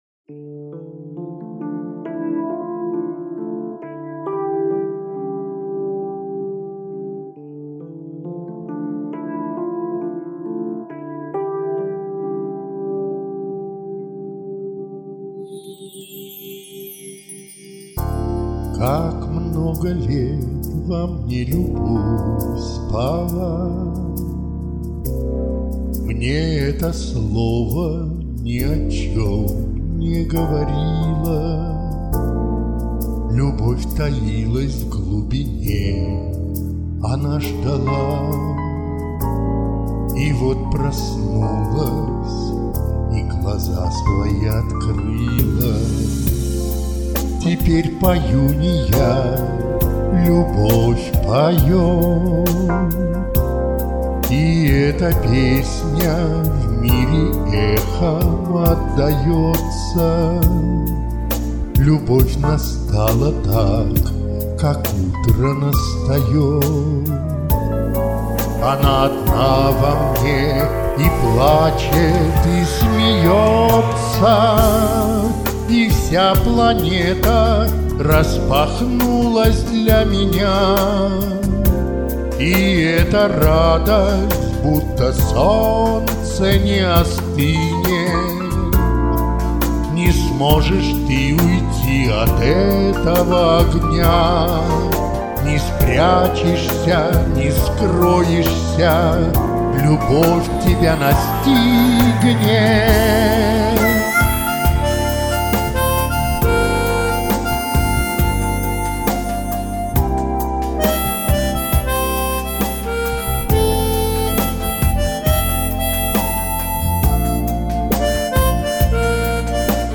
Очень приятные исполнения, душевные такие.